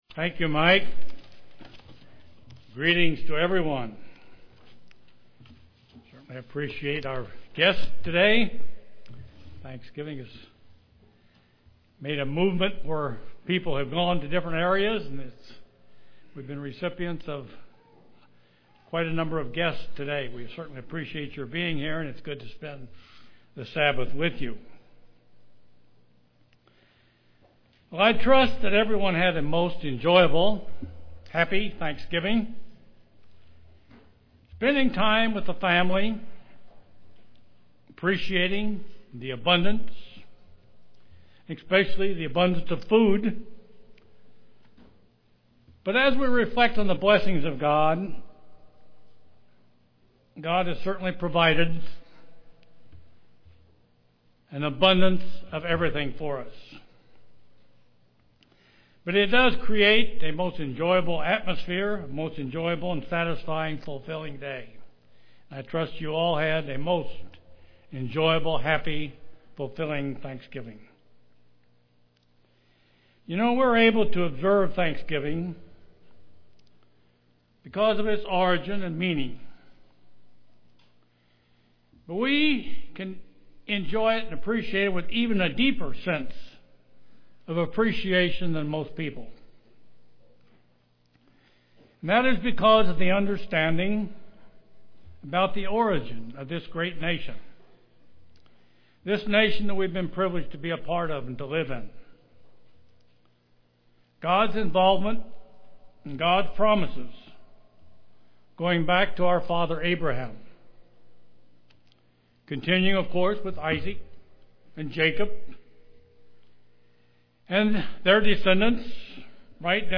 Given in Kansas City, KS
UCG Sermon Studying the bible?